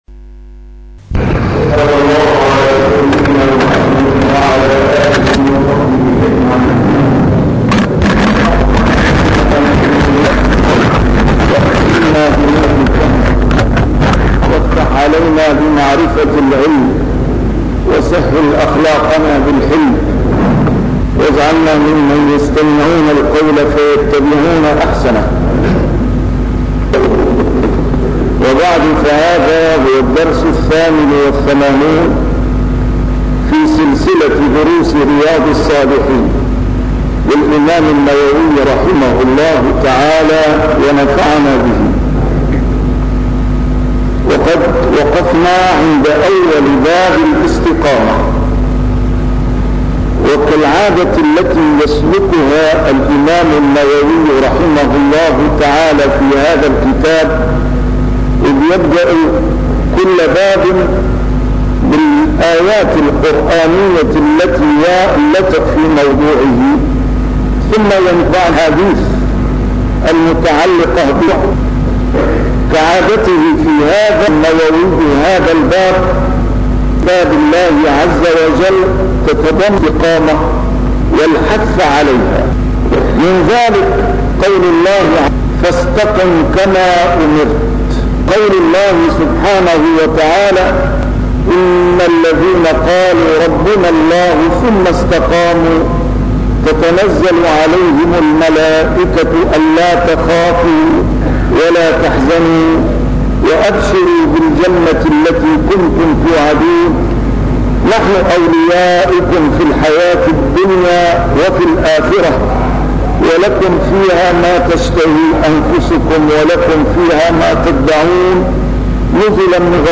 A MARTYR SCHOLAR: IMAM MUHAMMAD SAEED RAMADAN AL-BOUTI - الدروس العلمية - شرح كتاب رياض الصالحين - 88- شرح رياض الصالحين: الاستقامة